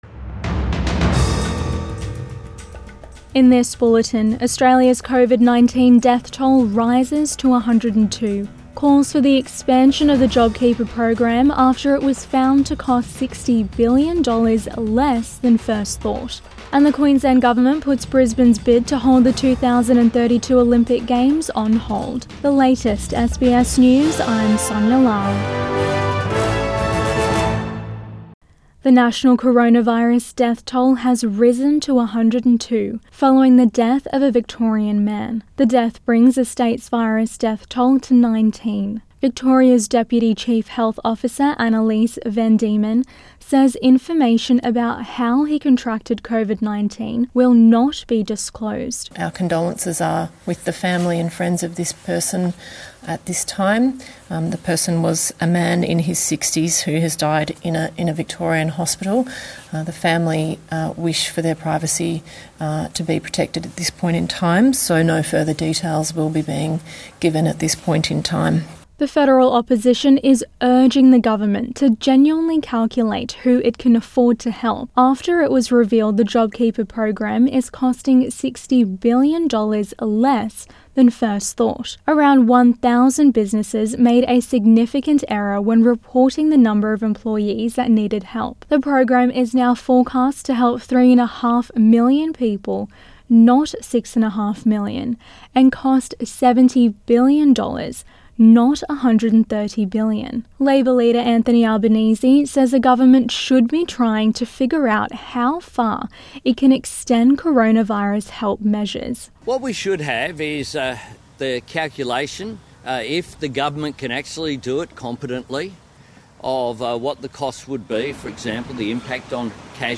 PM bulletin 23 May 2020